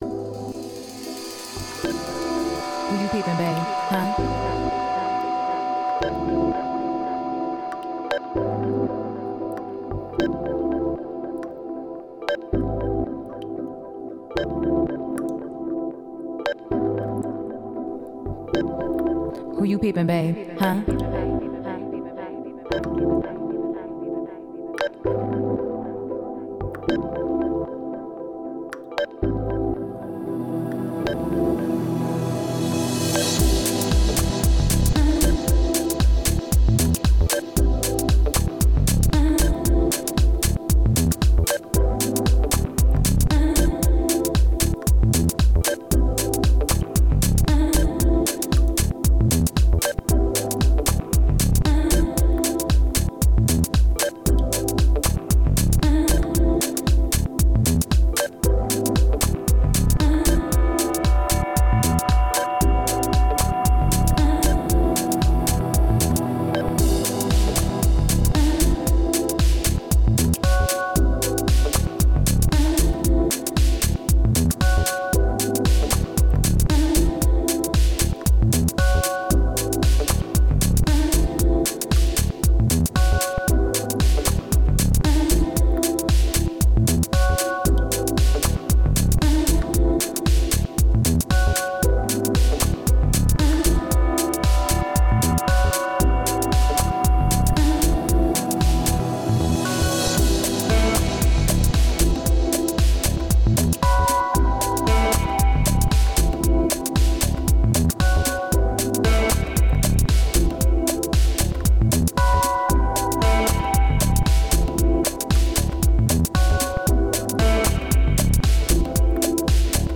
Genre: Deep House/Dub Techno.